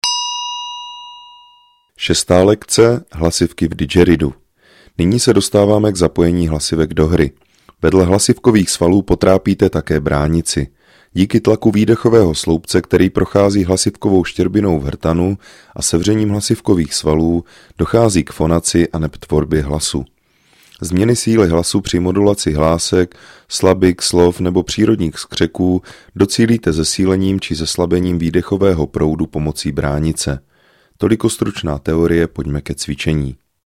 VÝUKA HRY NA DIDGERIDOO I.
Didgeridoo je nástroj, jehož hluboký tón dokáže ukotvit pozornost v přítomném okamžiku.
Track 24 - 6 lekce - Hlasivky v didgeridoo.mp3